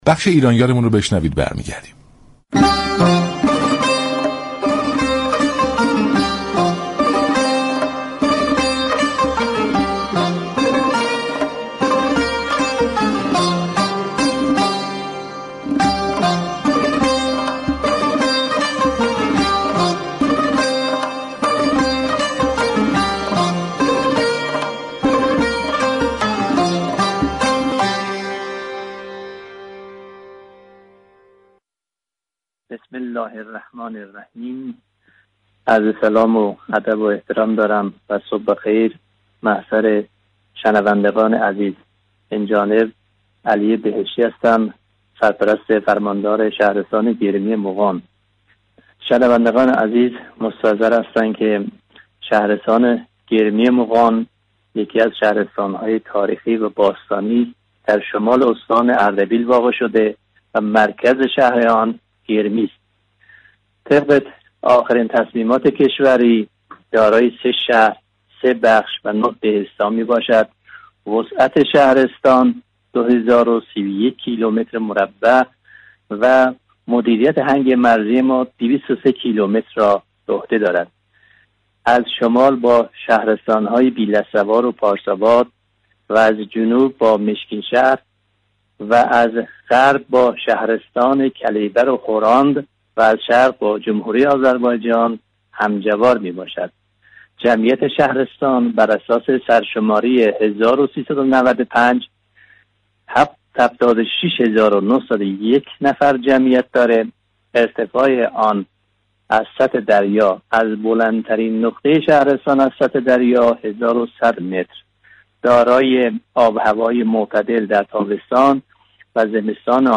علی بهشتی سرپرست فرمانداری گرمی مغان استان اردبیل به عنوان ایرانیار در برنامه سلام صبح بخیر رادیو ایران صحبت كرد.